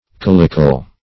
Calicle \Cal"i*cle\, n. [L. caliculus a small cup, dim. of